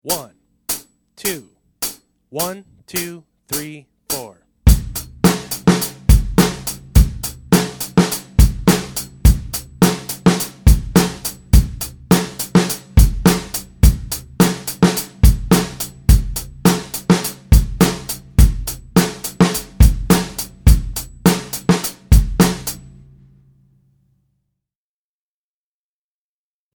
Voicing: Drum Set w